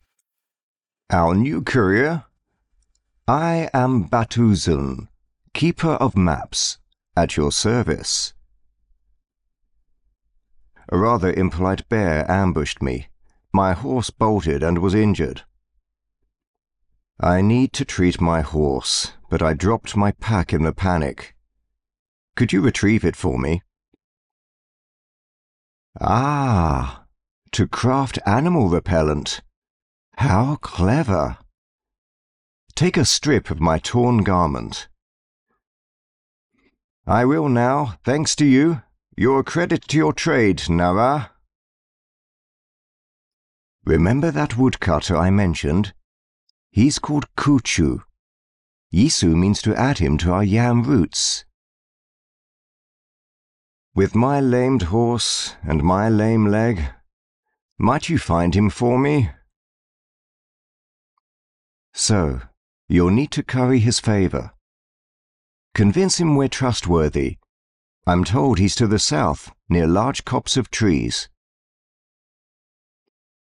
Male
Adult (30-50), Older Sound (50+)
His naturally deep, distinctively textured voice combines clarity, warmth and authority, making it well suited to commercials, explainers, corporate narration, training content and character roles.
Video Games
British English Male Video Game Mentor Character